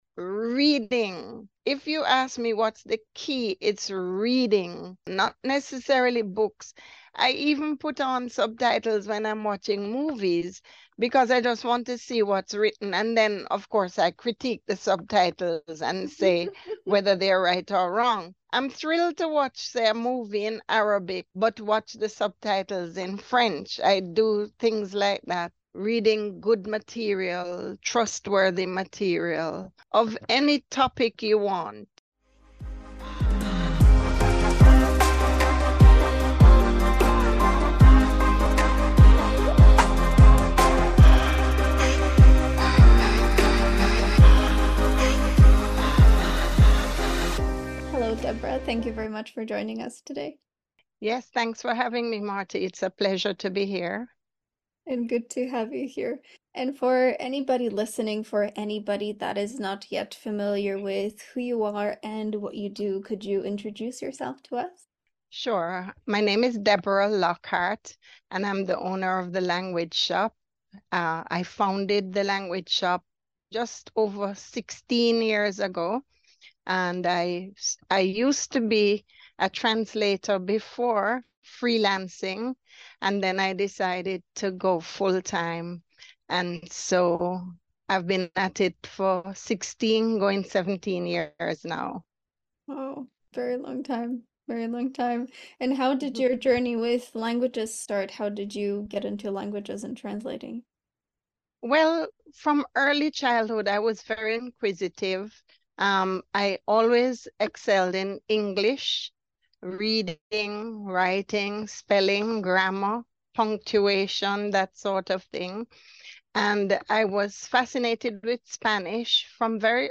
each episode features lively conversations about the principles of effective communication, sharing tips and tricks directed at not only non-native English speakers, but anybody that wants to create better, lasting connections with people. With language teachers and learners as guests, they share their top study tips, methods for staying motivated while learning a new language, as well as their key MINDSET shifts and tricks to stay positive during your journey.